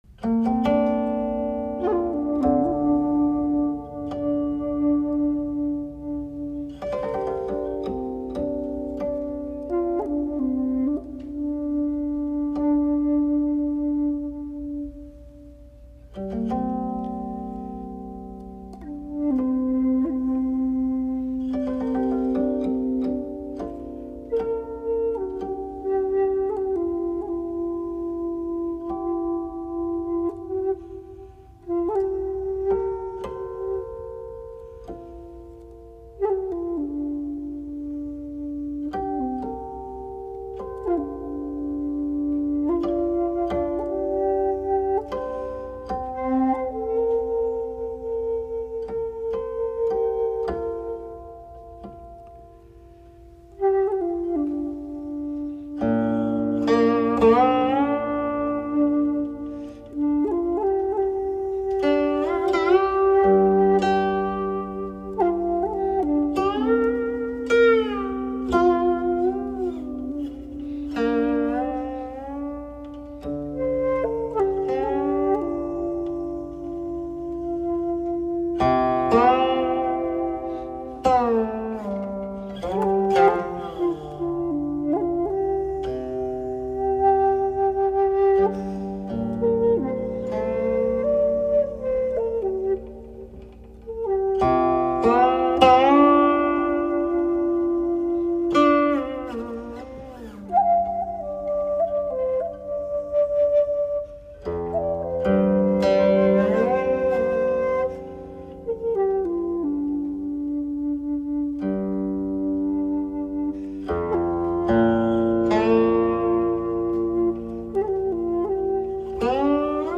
音乐类型：民乐 古琴